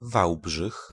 kiejtése; (németül Waldenburg) város Lengyelországban, az Alsó-sziléziai vajdaságban, a Wałbrzychi járásban.
Pl-Wałbrzych.ogg